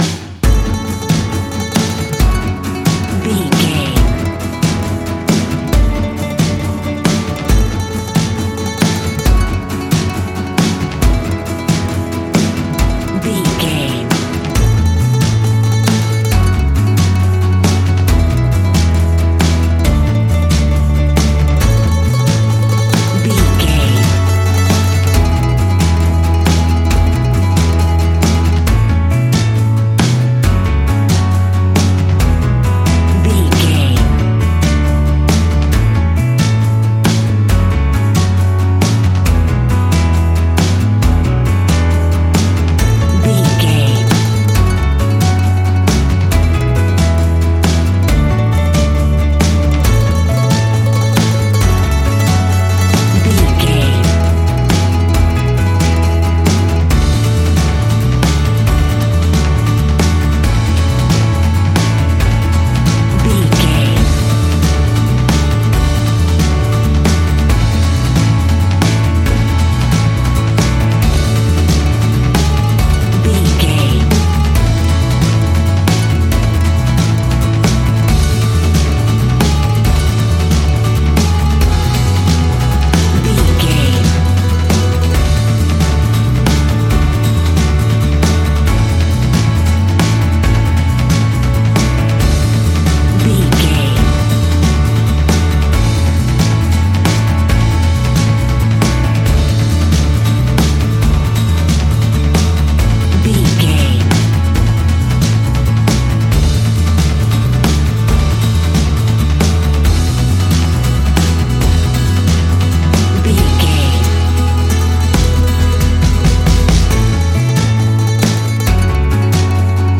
Ionian/Major
earthy
acoustic guitar
mandolin
ukulele
lapsteel
drums
double bass
accordion